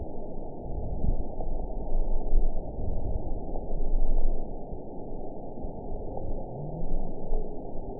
event 921744 date 12/18/24 time 08:18:26 GMT (6 months ago) score 5.01 location TSS-AB03 detected by nrw target species NRW annotations +NRW Spectrogram: Frequency (kHz) vs. Time (s) audio not available .wav